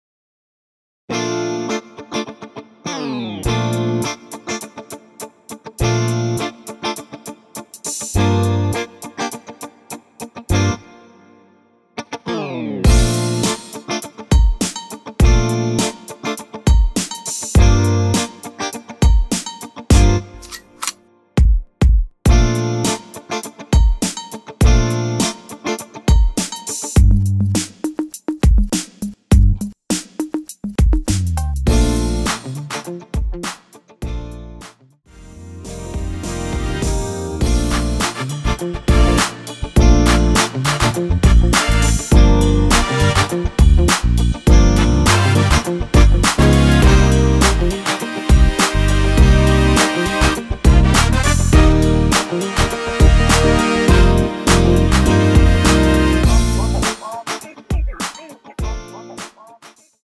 전주 없이 시작 하는 곡이라서 1마디 전주 만들어 놓았습니다.(미리듣기 확인)
3초쯤 노래 시작 됩니다.
원키에서(-3)내린 MR입니다.
Bb
앞부분30초, 뒷부분30초씩 편집해서 올려 드리고 있습니다.